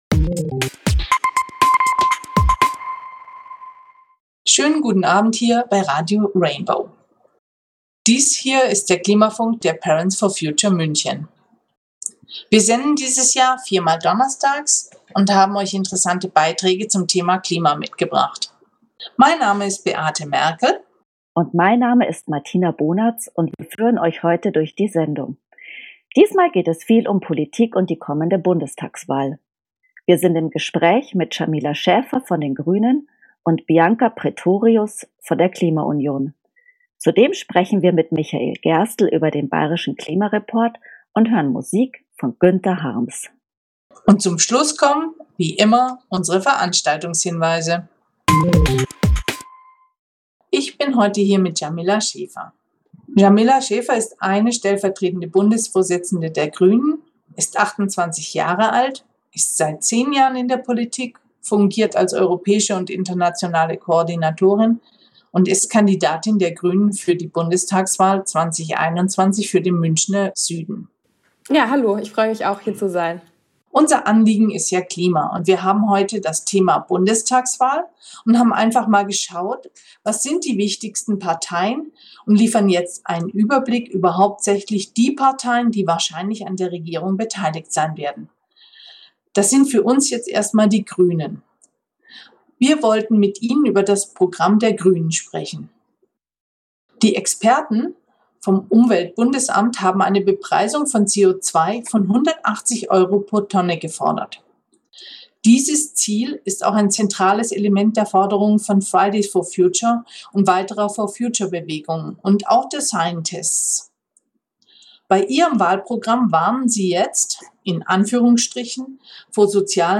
Interviews mit Jamila Schäfer
ein Gespräch